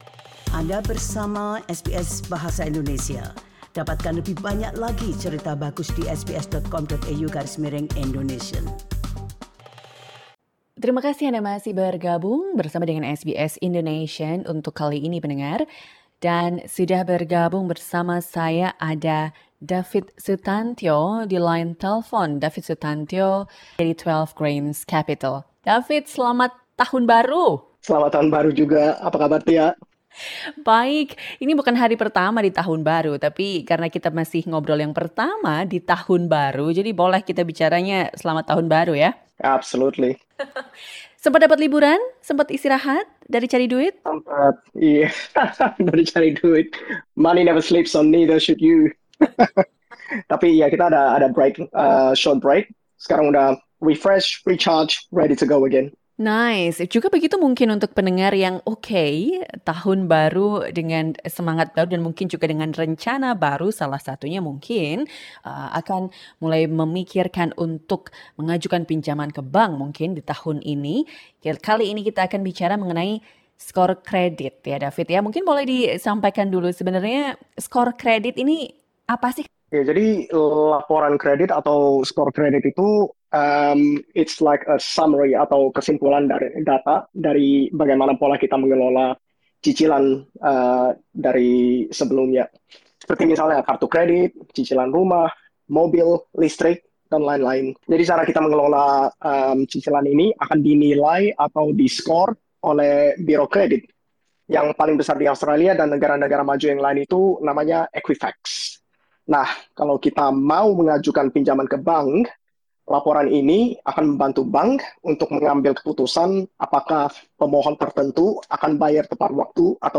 Here is a snippet of the interview.